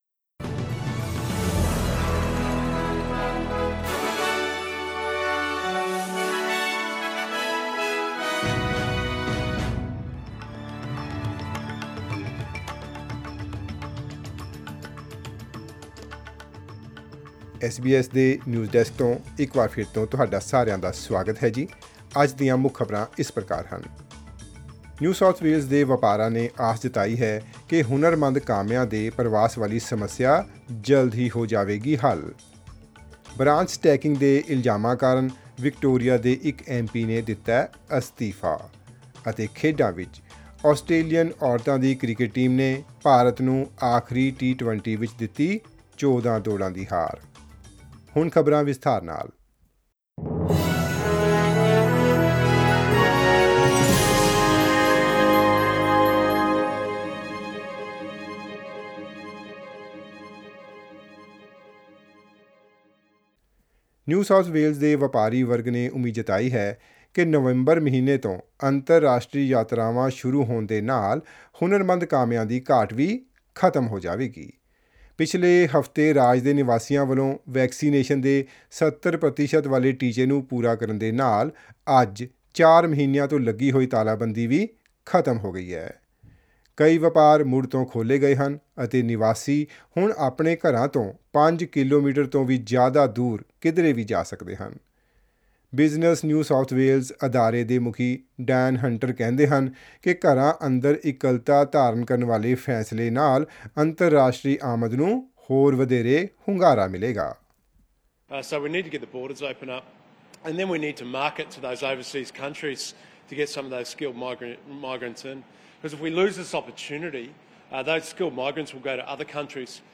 Punjab is once again staring at major power outages with its thermal plants heading to deficiency of coal. As a result, Punjab State Power Corporation Limited (PSPCL) has resorted to power cuts for two to six hours. This and more in our weekly news update from the north Indian state.